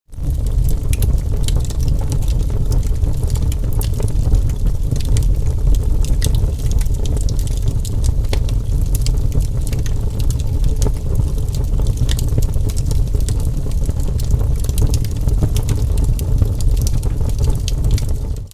Звуки на звонок